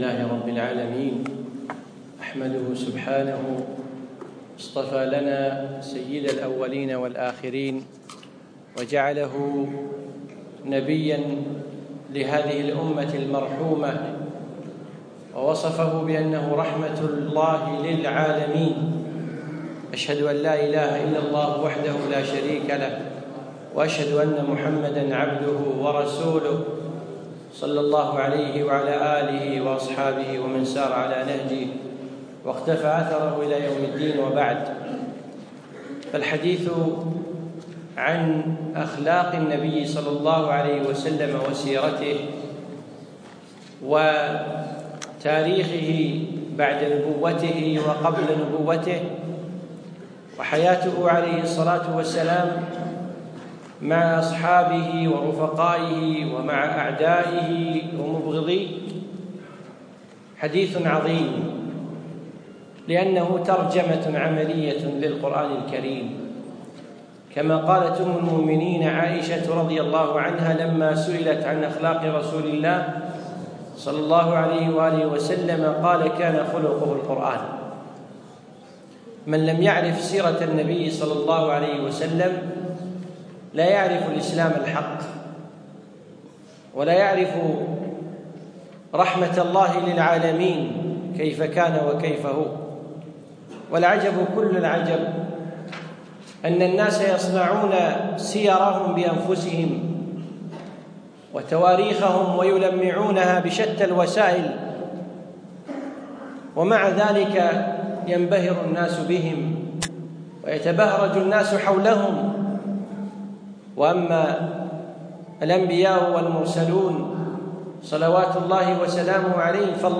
يوم الخميس 25 ربيع الأخر 1437هـ الموافق 4 2 2016م في مسجد فهد سند العجمي جمعية خيطان
الدرس الأول